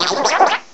sovereignx/sound/direct_sound_samples/cries/pyukumuku.aif at 2f4dc1996ca5afdc9a8581b47a81b8aed510c3a8